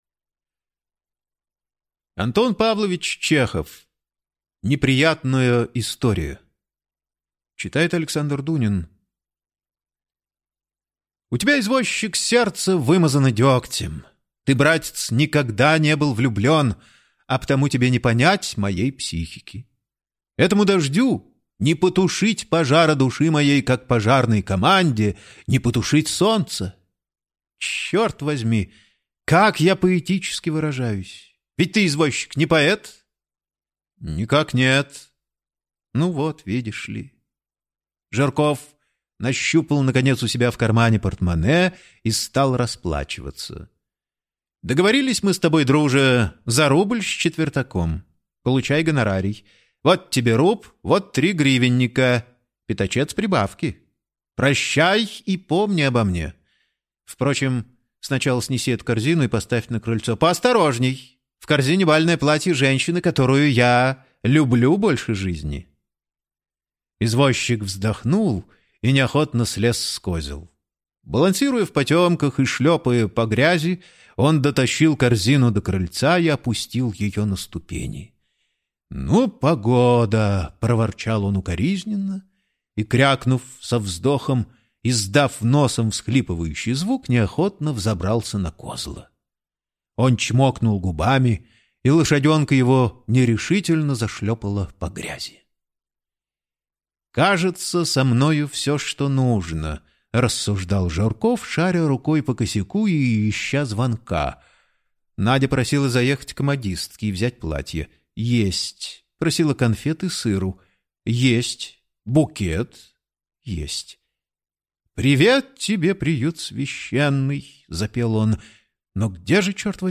Аудиокнига Неприятная история | Библиотека аудиокниг